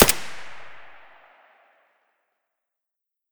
shoot_sil.ogg